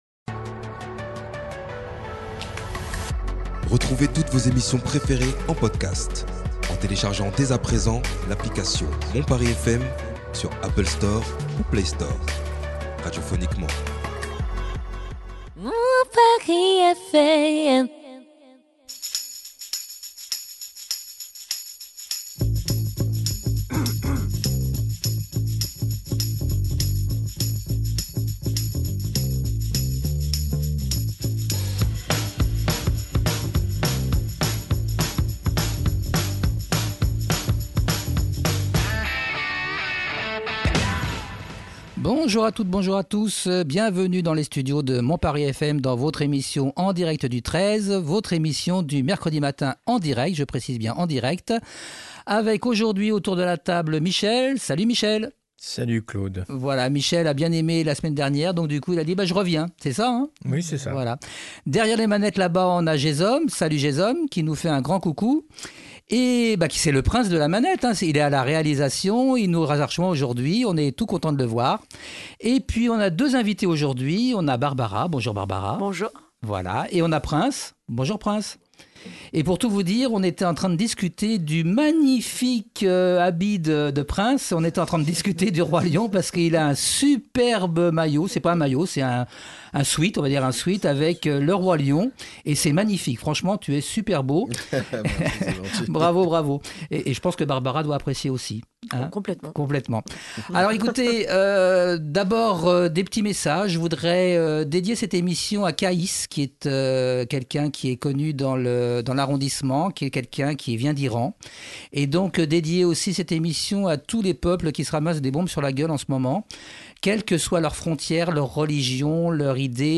Autant tout vous dire, l'association Origine que nous recevons aujourd'hui est bien connue au centre Paris Anim Oudiné d'où nous émettons notre émission, puisque nos invités et leur équipe interviennent régulièrement dans nos locaux.